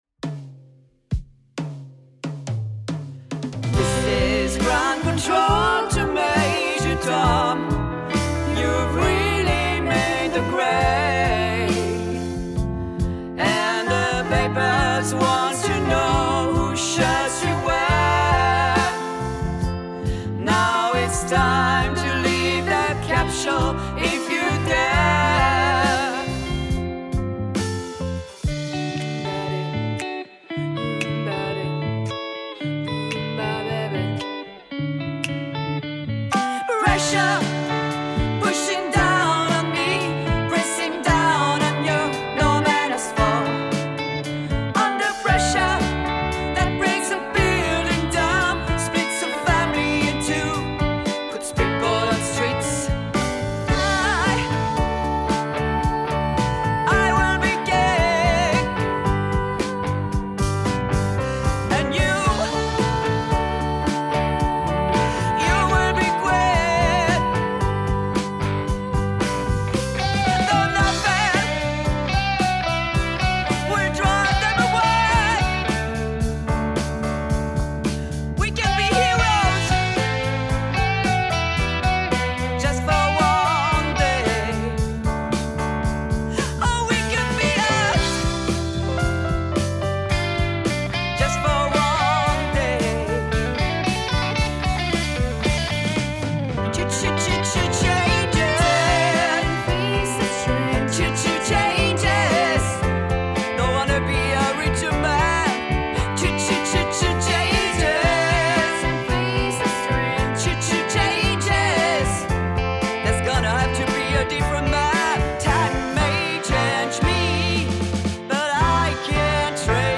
six musiciens professionnels donnent vie à cet hommage
batteur et choriste
guitariste soliste
pianiste virtuose et pétillant